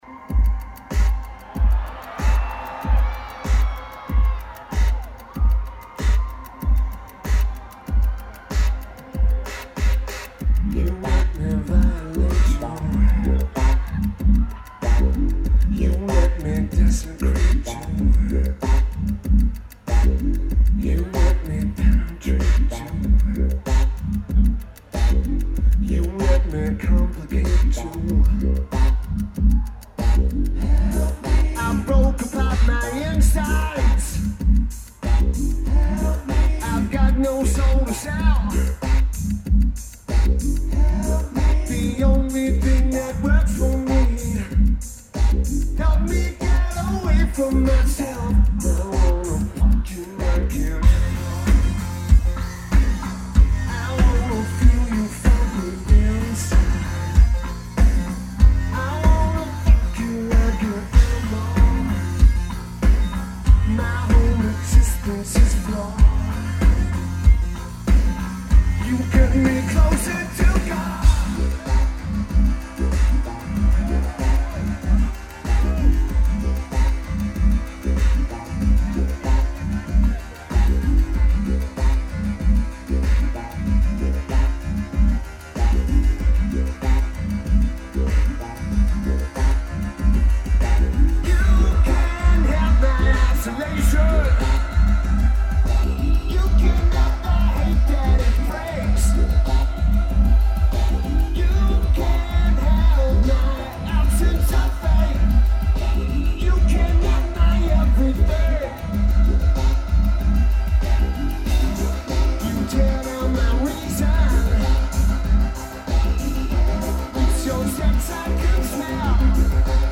Keyboards/Bass/Backing Vocals
Drums
Guitar